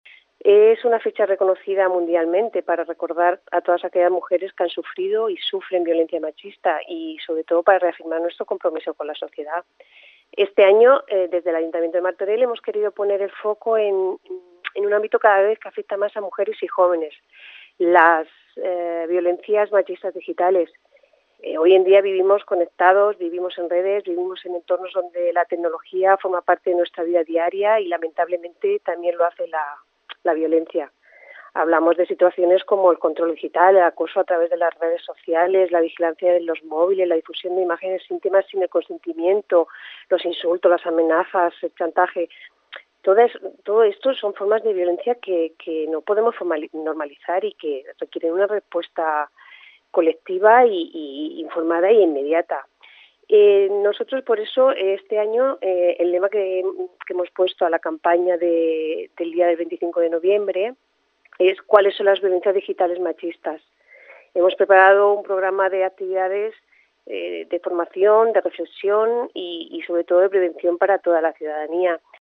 Remedios Márquez, regidora d'Igualtat